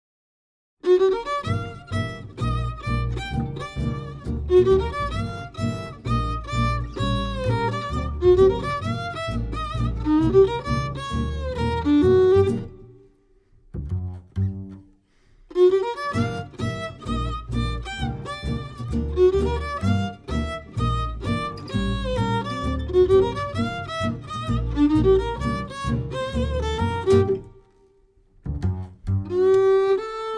violino
chitarra semiacustica
chitarra acustica
contrabbasso
un pezzo mid-tempo